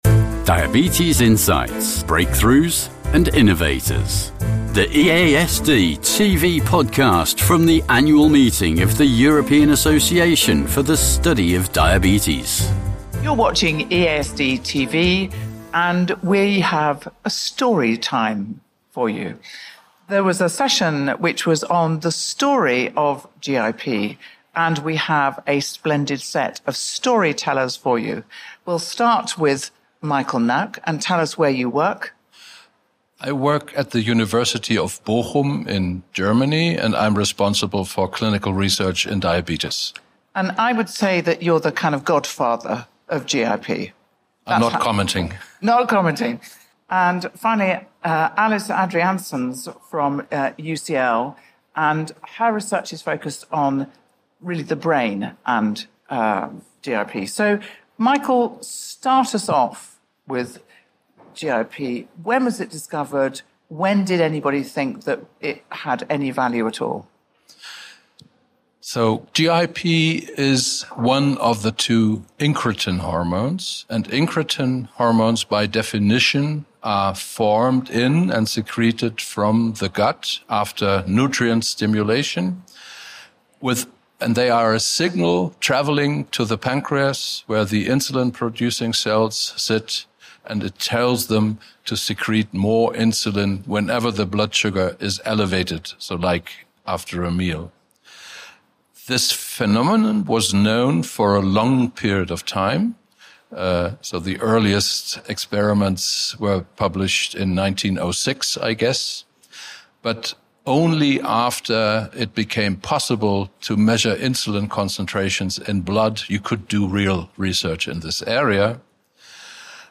Join us for a practical, inspiring conversation about driving real change in healthcare systems.